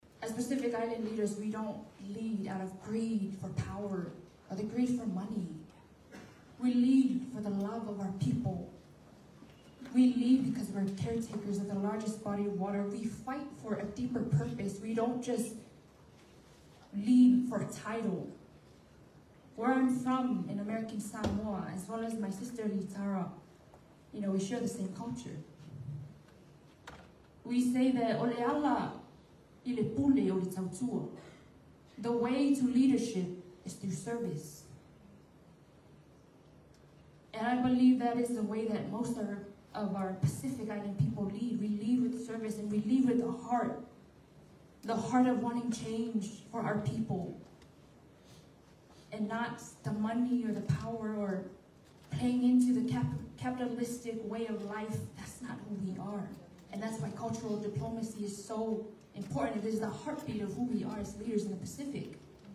She fielded two questions from the judges, one of which was the role of cultural diplomacy in international relations.